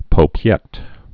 (pō-pyĕt)